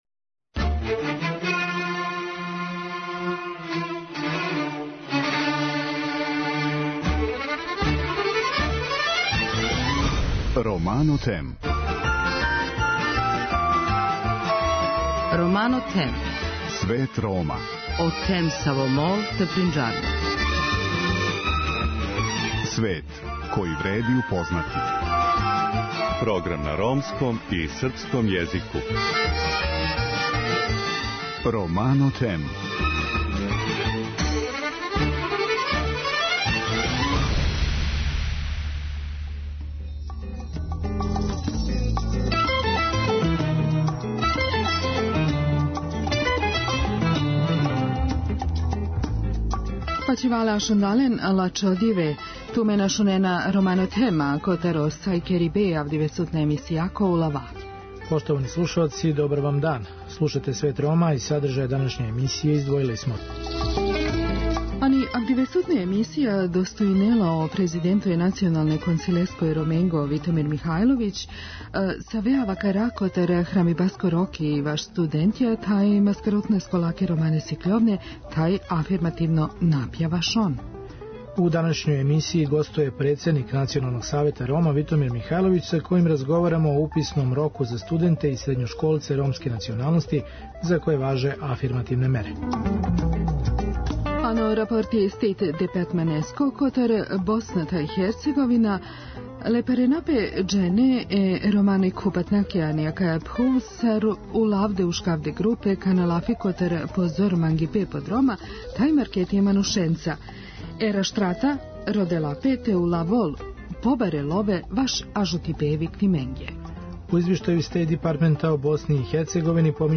У данашњој емисији гостује председник Националног савета Рома, Витомир Михајловић са којим разговарамо о уписном року за студенте и средњошколце ромске националности за које важе афирмативне мере.